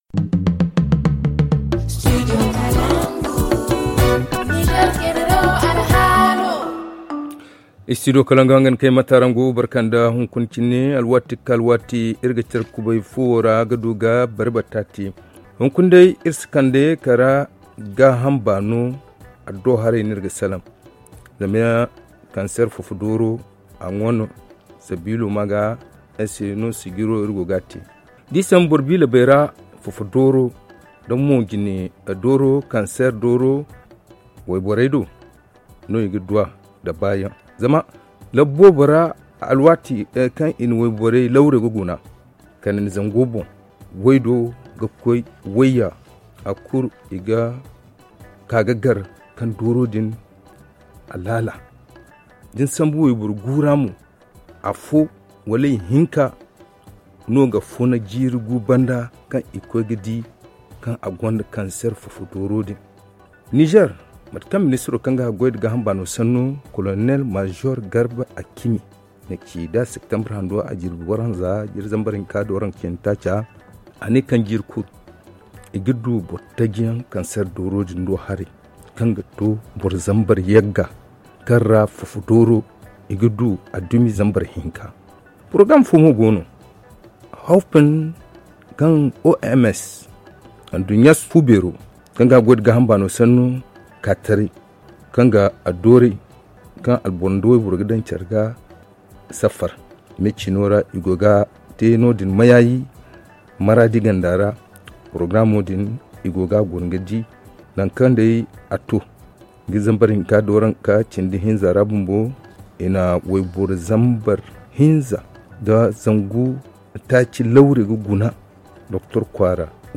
sage-femme
ZA Le forum en zarma Télécharger le forum ici.